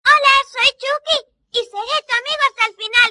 Cine y Televisión / Tonos Divertidos
Chucky, el muñeco diabólico en una de sus primeras intervenciones en la película estrenada en el año 1988.